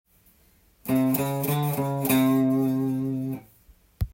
クロマチックフレーズTAB譜
①のフレーズは、C7の１度の音に
クロマチックスケールを使い着地させたものです。